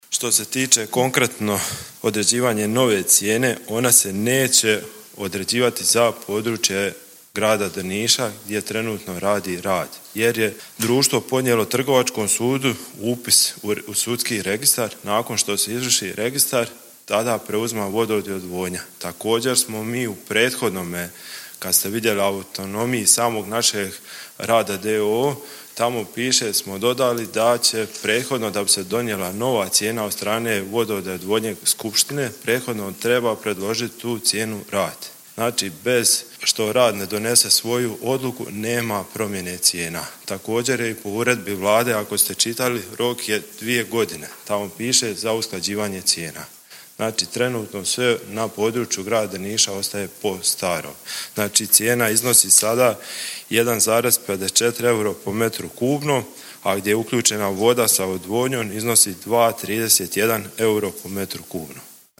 Voda Drnišanima neće poskupjeti – kazao je gradonačelnik Drniša Tomislav Dželalija na sinoć održanoj sjednici gradskog vijeća odgovarajući na pitanje vijećnika Antonia Marina: